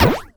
sci-fi_weapon_laser_small_fun_04.wav